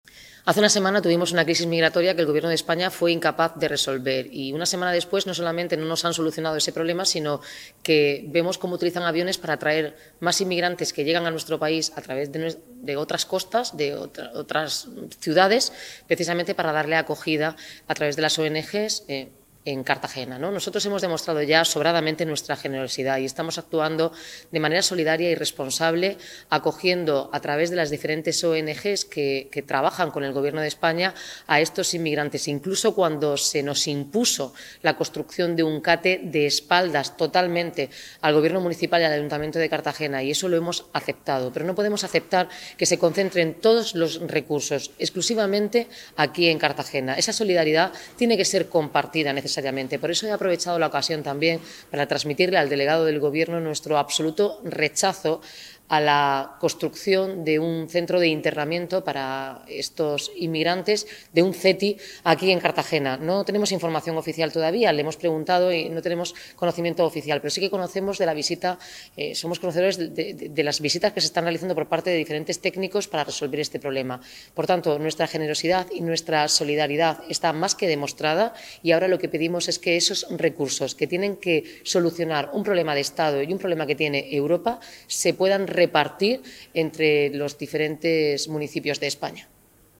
Audio: Pleno ordinario primera parte (sesi�n matinal).